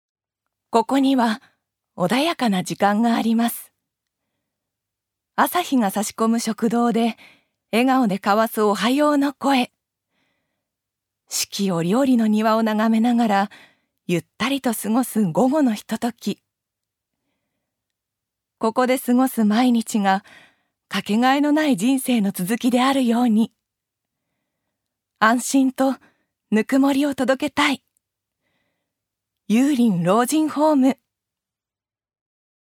ジュニア：女性
ナレーション１